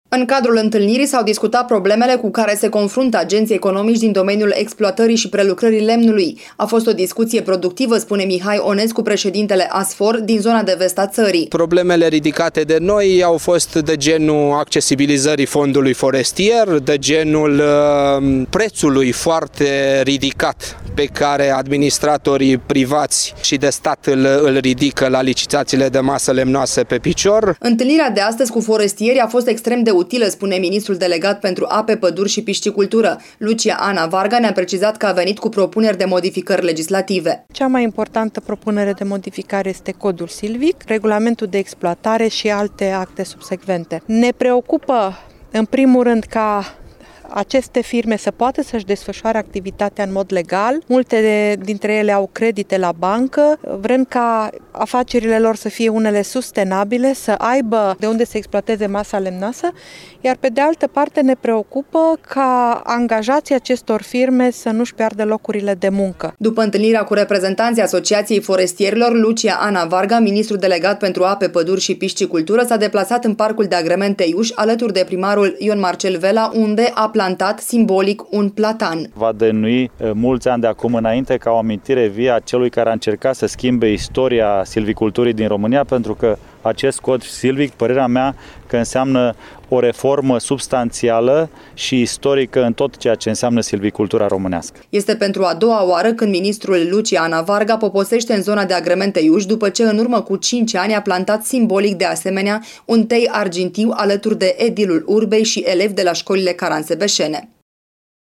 Mai multe detalii despre vizita la Caransebeş, aflaţi din următorul reportaj